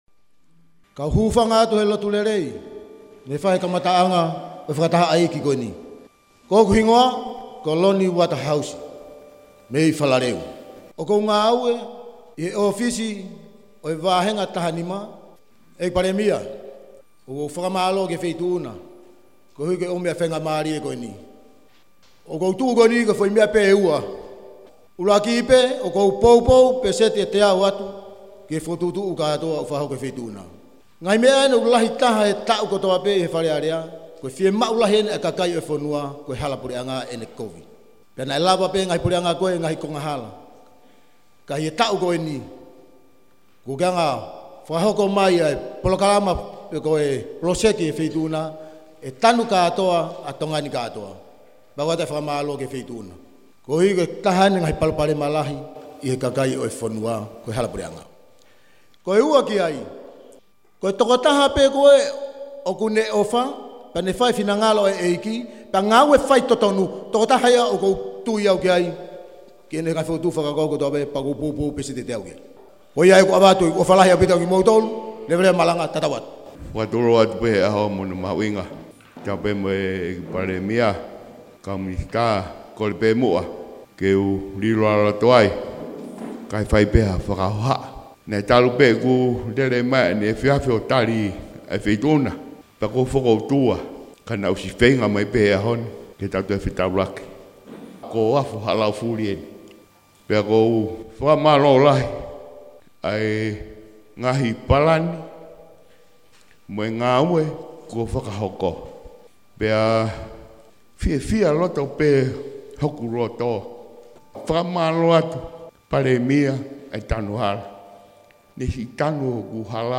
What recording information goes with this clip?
An audio of the Prime Minister’s meeting in Vava’u was provided by the Prime Minister’s office and transcribed and translated into English by Kaniva News.